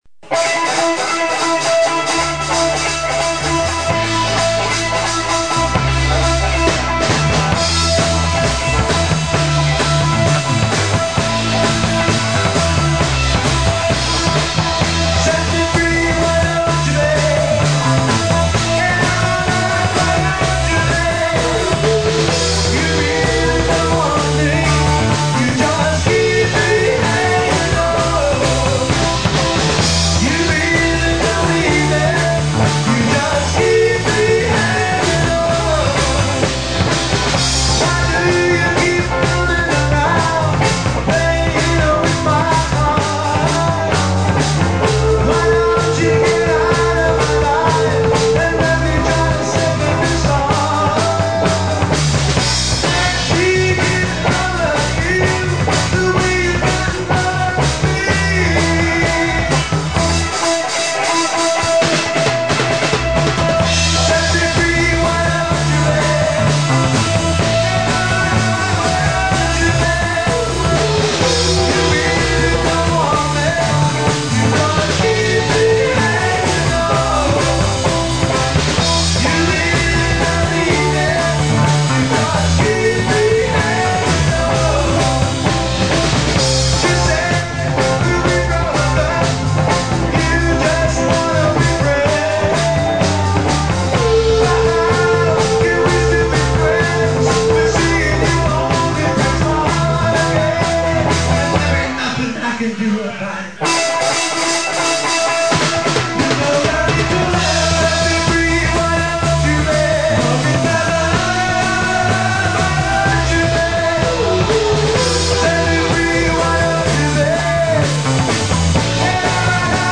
CLASSIC ROCK